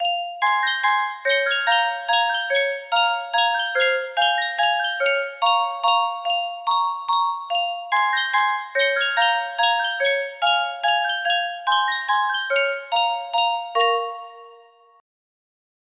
Yunsheng Music Box - Scheherazade Op. 35 5129 music box melody
Full range 60